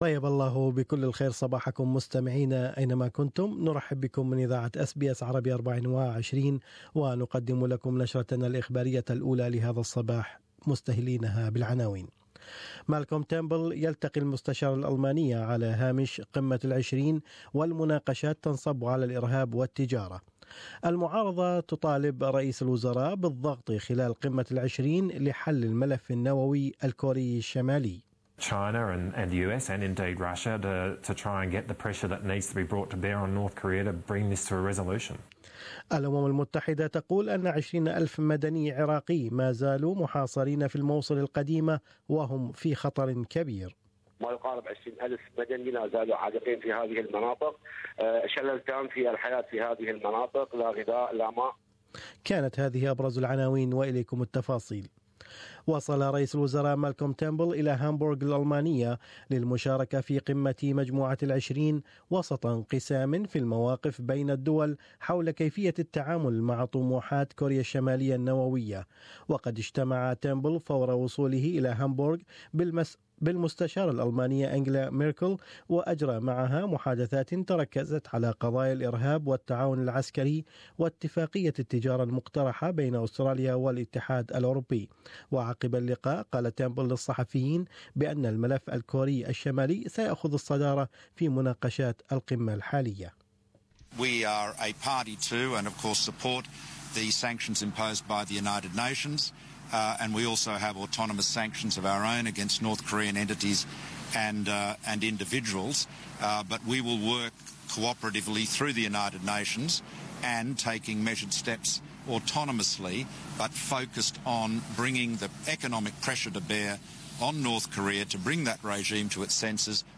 نشرة الاخبار: تيرنبول يشارك بقمة العشرين في المانيا وملفات الارهاب والتجارة وكوريا الشمالية على جدول اعمال القمة